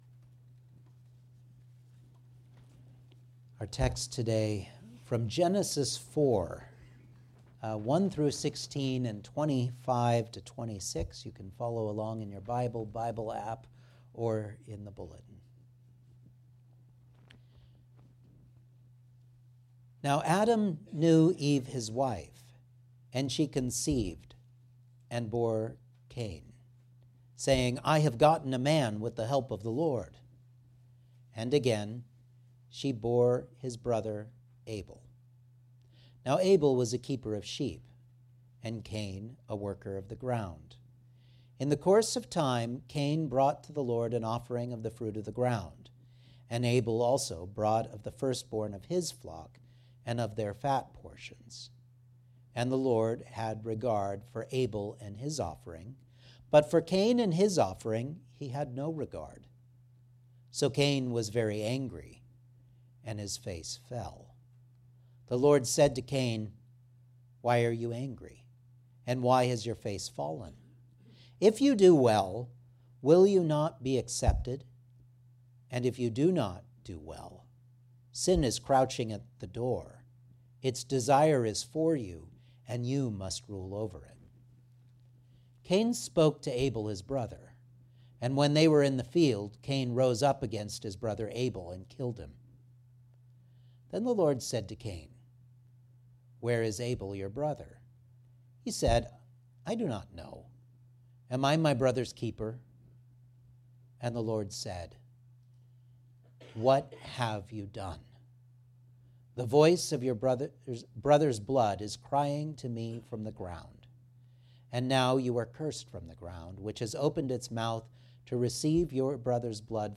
25-26 Service Type: Sunday Morning Outline